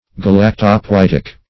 Search Result for " galactopoietic" : The Collaborative International Dictionary of English v.0.48: Galactopoietic \Ga*lac`to*poi*et"ic\, a. [Gr.
galactopoietic.mp3